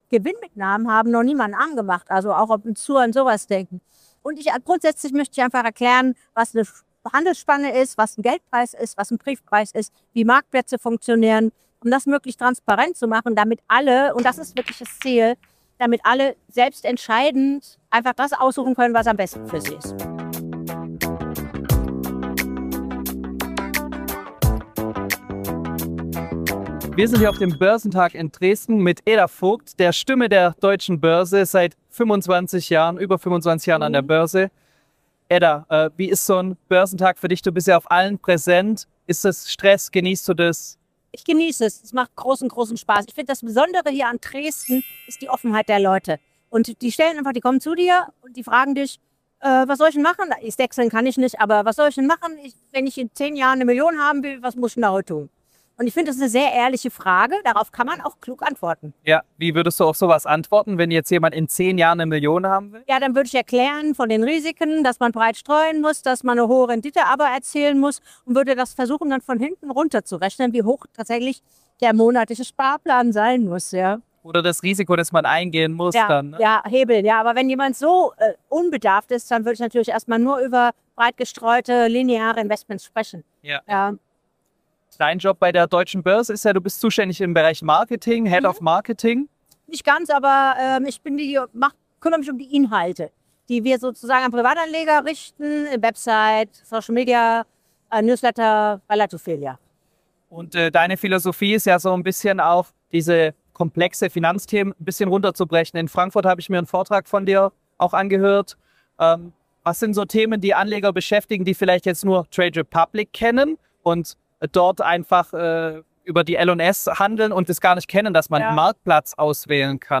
Du lernst, warum „0€-Order“ durch weite Spreads und falsche Handelszeiten teuer werden kann – und wie Xetra, Stop-Loss & Trailing Stop deine Rendite schützen In dieser Folge vom Börsentag Dresden erfährst du, wie du Handelsplätze, Geld‑/Brief‑Spannen und Orderzeiten richtig einordnest, warum Xetra oft der fairste Referenzmarkt ist und wie ein Trailing Stop Loss deine Gewinne automatisch absichert.